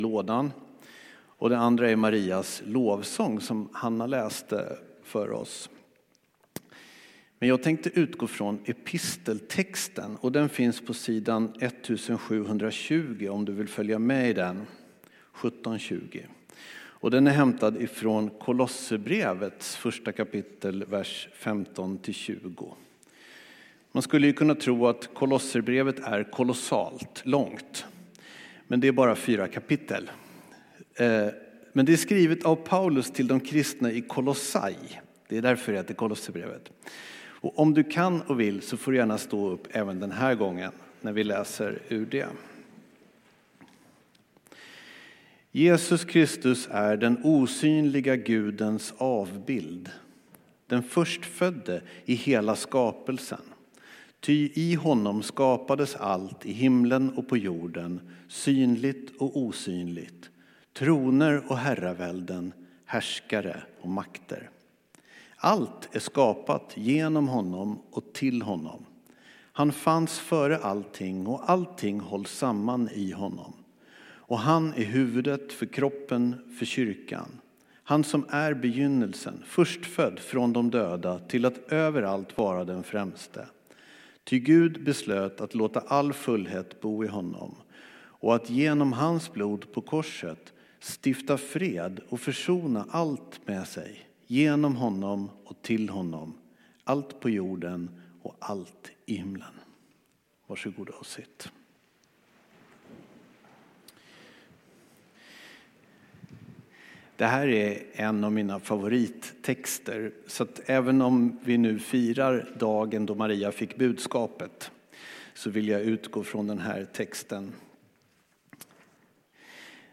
Här hittar du inspelningar från gudstjänster och andra tillställningar i Abrahamsbergskyrkan i Bromma i Stockholm. Av upphovsrättsliga skäl rör det sig huvudsakligen om predikningar.